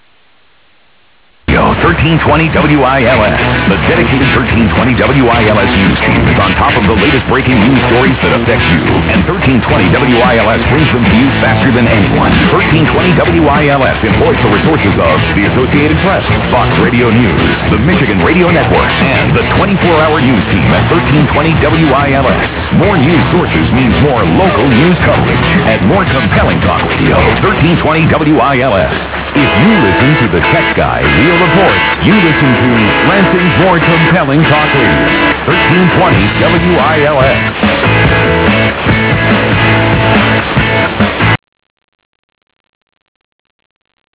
This page contains DX Clips from the 2008 DX season!